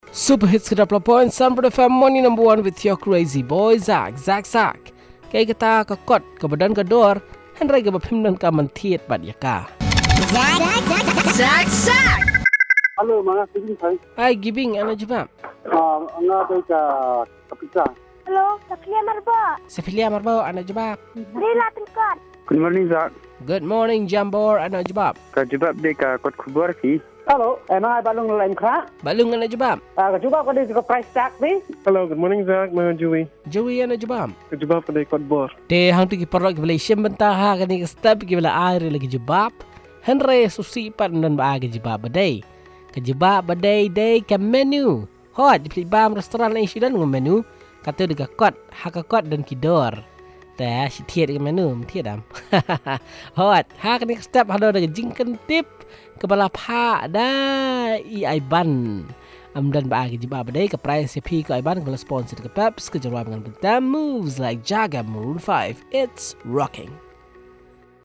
Calls and result